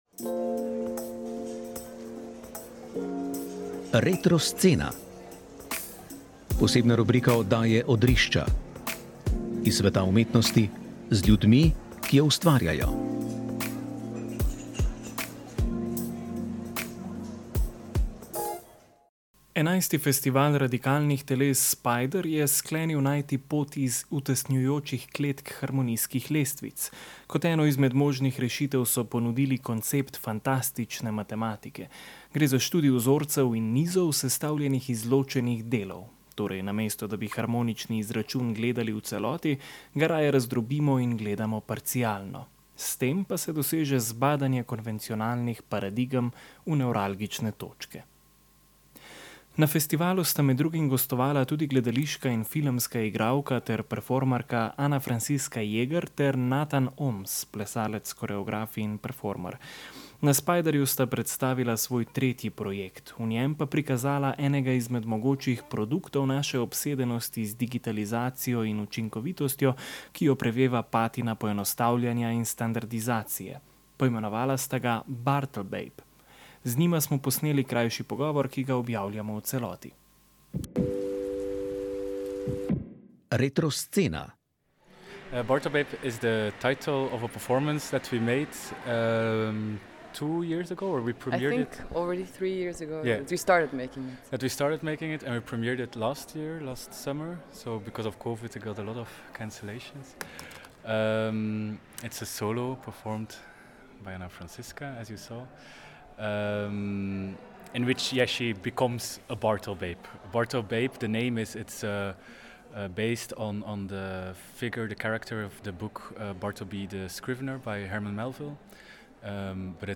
Odrišča so bila na 11. Festivalu radikalnih teles Spider.